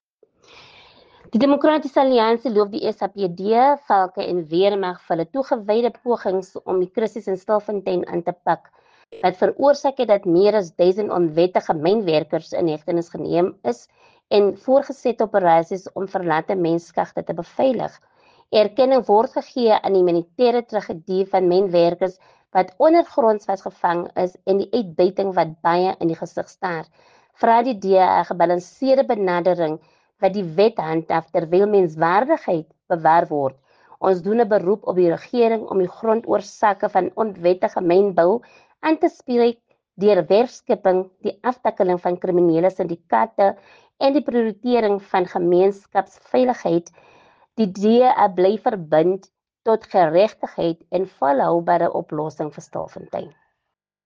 Note to Broadcasters: Please find attached soundbites in